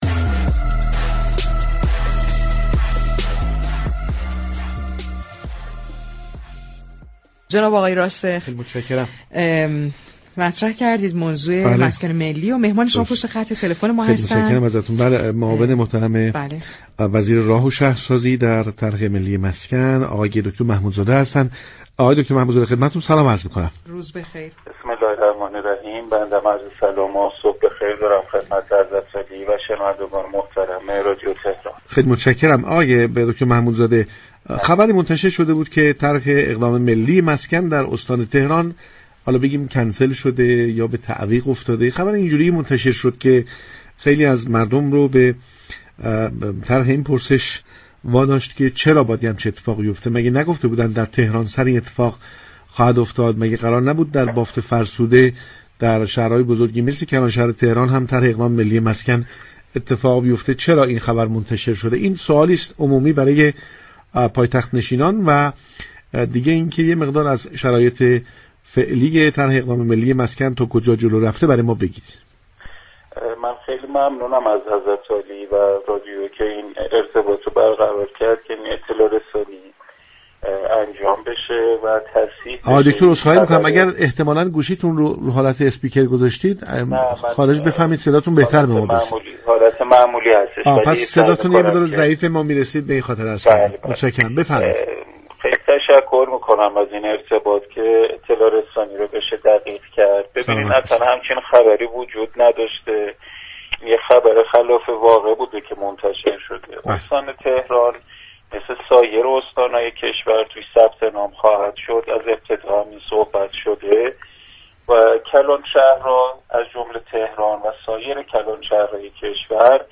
محمود محمودزاده معاون وزیر راه‌و‌شهرسازی در امور مسكن و ساختمان در گفتگو با پارك شهر درباره طرح ملی مسكن سخن گفت.